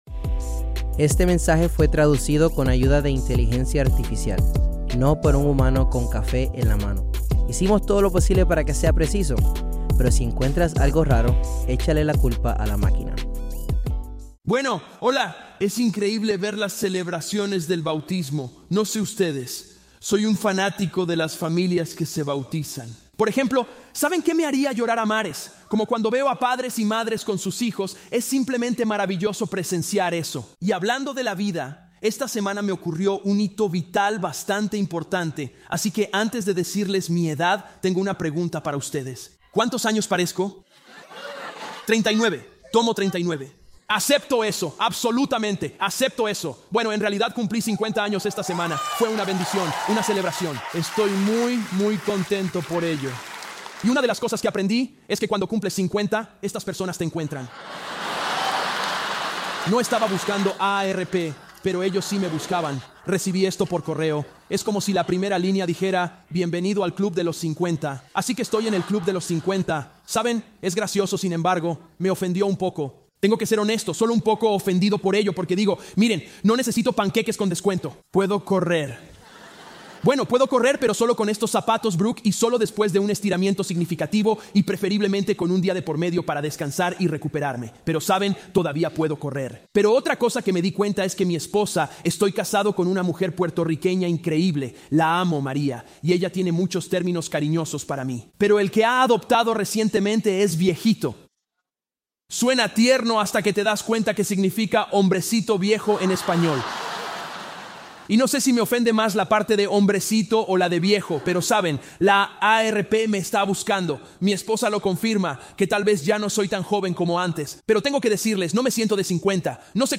Grabado en vivo en Crossroads Church en Cincinnati, Ohio.